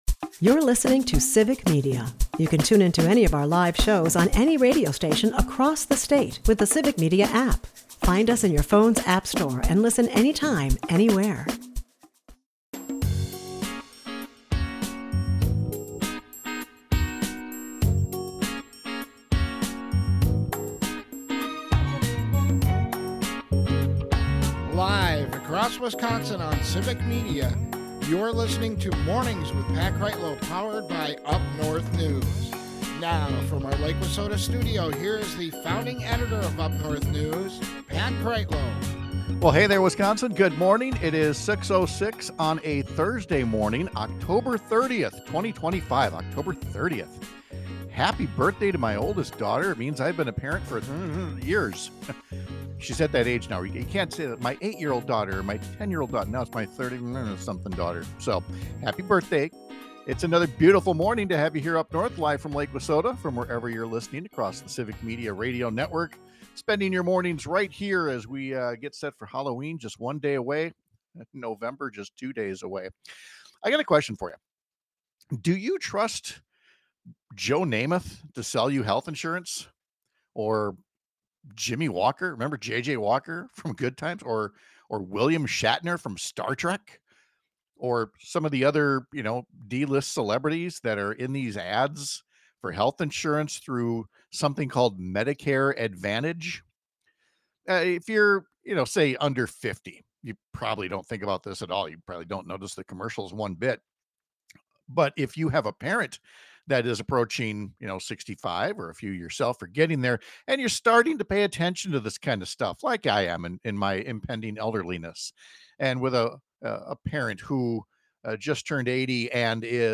Of course, this was in December of last year and Van Orden has since removed any pretense of being a moderate, but we’ll play back the audio and ask if you agree with him — even if he doesn’t agree with himself any longer.